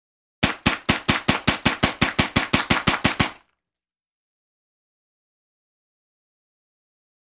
Paint ball Guns; Rapid Multiple Fire.